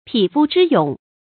注音：ㄆㄧˇ ㄈㄨ ㄓㄧ ㄩㄥˇ
匹夫之勇的讀法